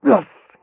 m_pain_5.ogg